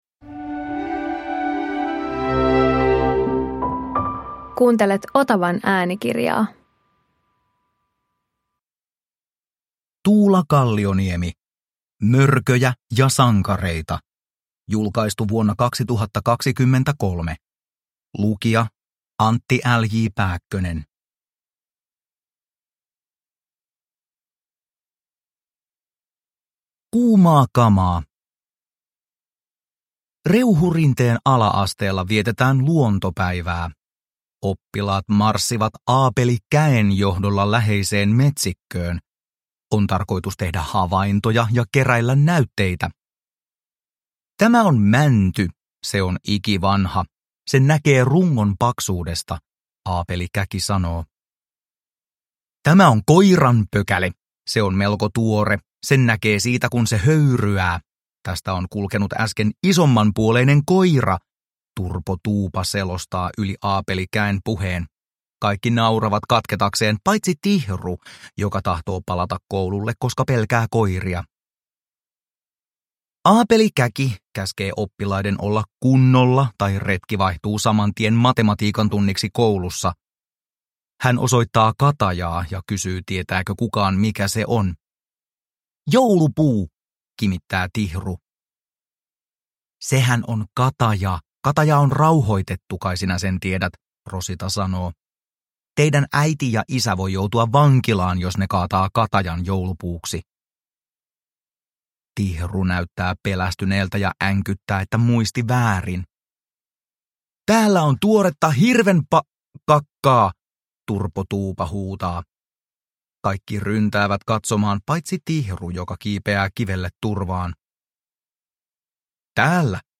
Mörköjä ja sankareita – Ljudbok – Laddas ner